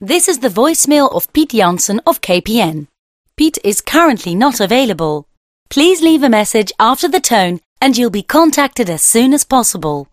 Beluister de professionele stem
KPN_BVM_Voorbeeld_Stem_UK.MP3